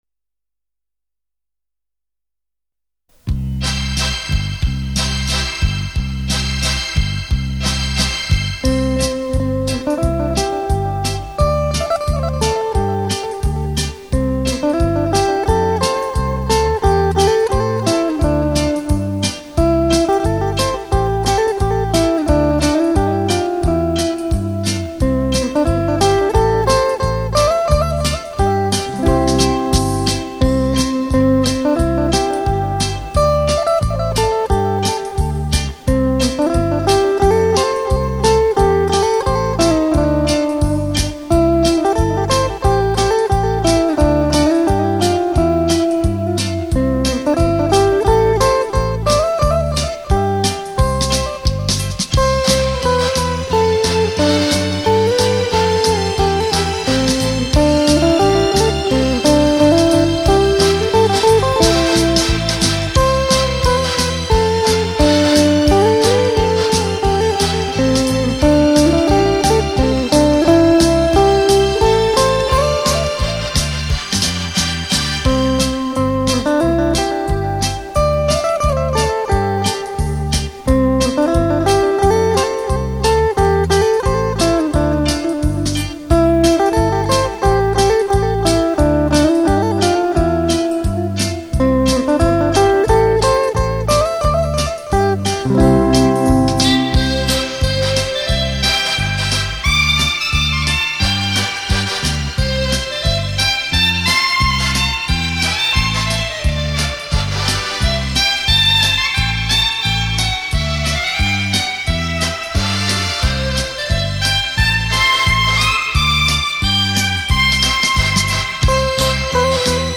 * Thể loại: Ngoại Quốc